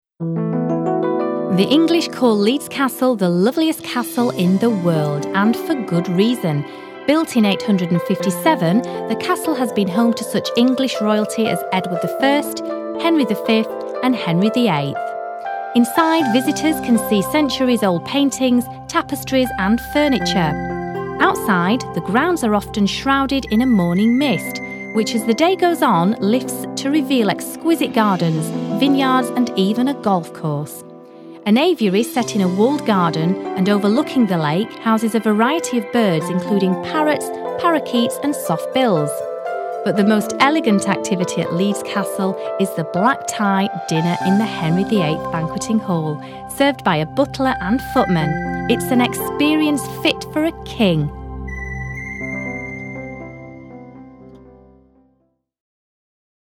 britisch
Sprechprobe: Werbung (Muttersprache):